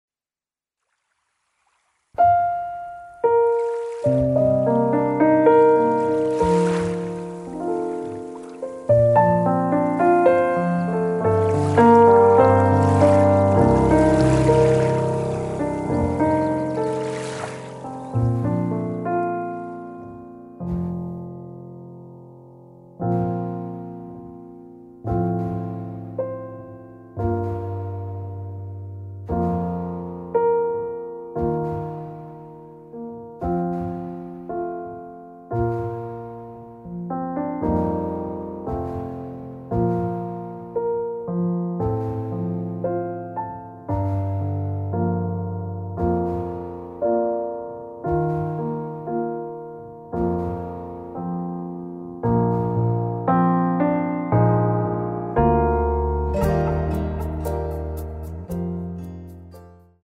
歌曲调式：降B调